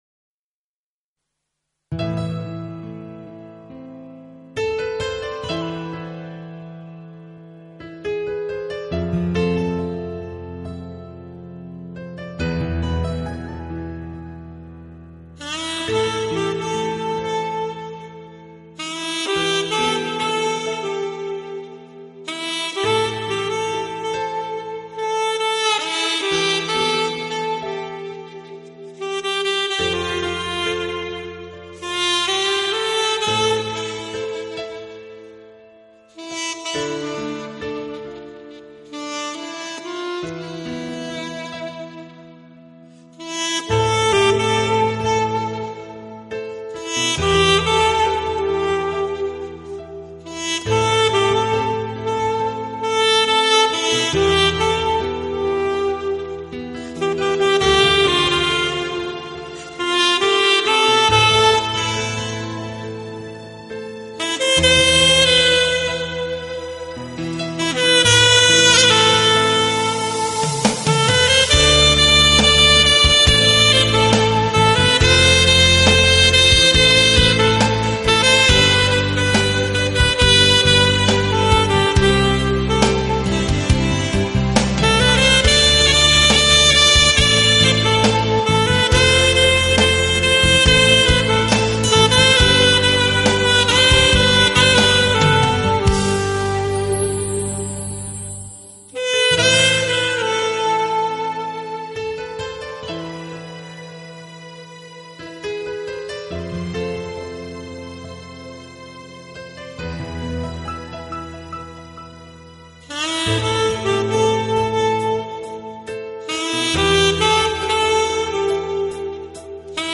我的爱因你而存在缠绵的萨克斯乐曲，演绎浪漫的情人物语，
这丝丝情意像恋人般彼此依偎，轻轻地，静静地直到永远！